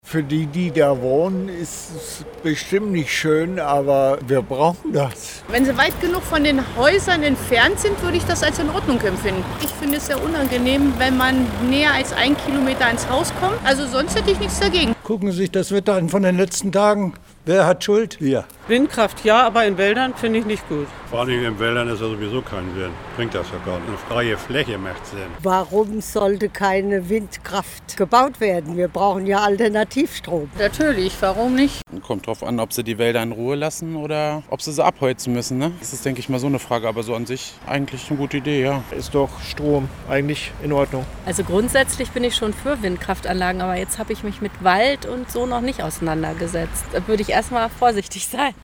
Landkreis Hameln-Pyrmont: UMFRAGE WINDKRAFT